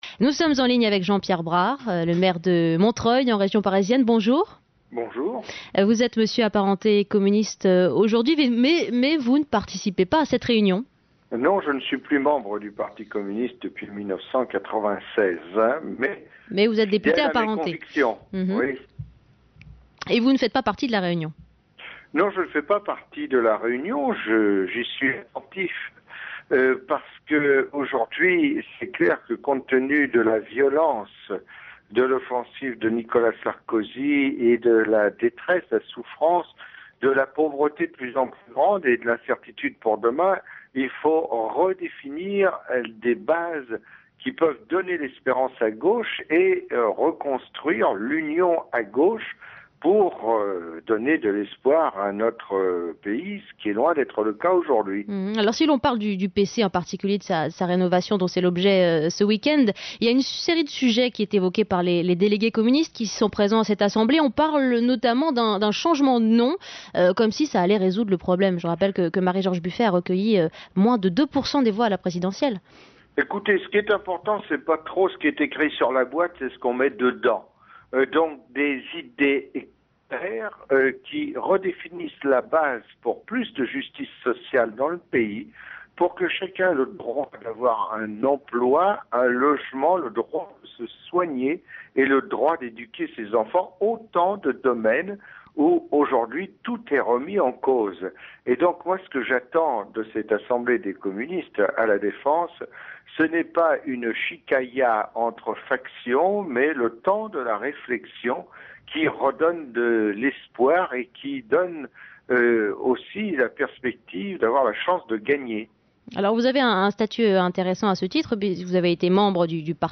La réaction de Jean-Pierre Brard, maire de Montreuil apparenté communiste au micro de Wendy Bouchard.